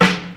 • '90s Hip-Hop Steel Snare Drum Sound F Key 09.wav
Royality free snare tuned to the F note.
90s-hip-hop-steel-snare-drum-sound-f-key-09-iyQ.wav